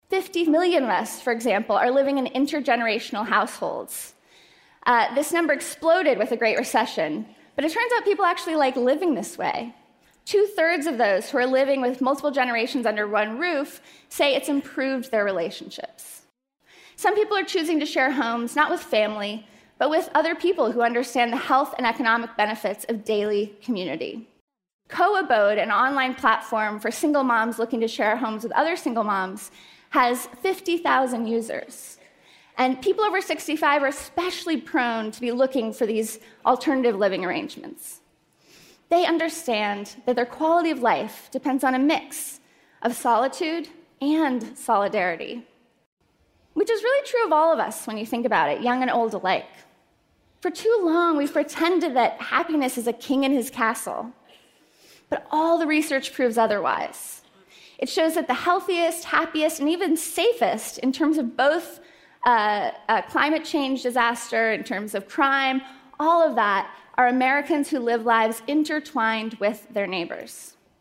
TED演讲:新美国梦(7) 听力文件下载—在线英语听力室